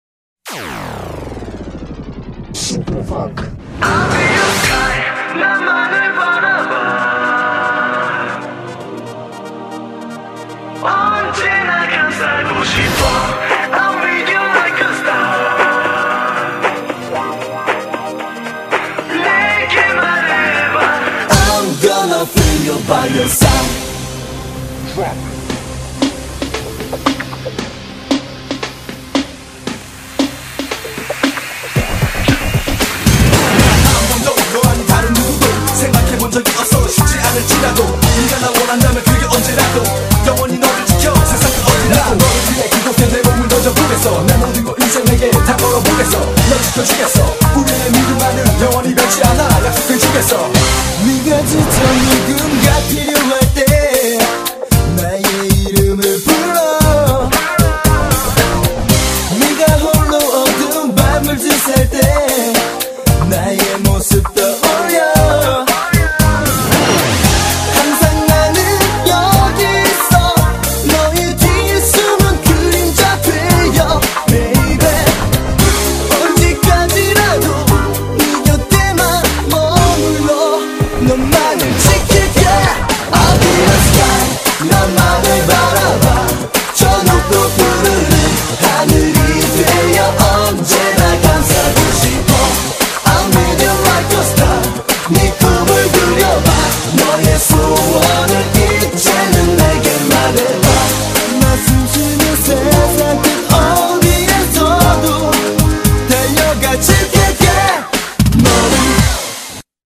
K-POP
BPM114--1
Audio QualityPerfect (High Quality)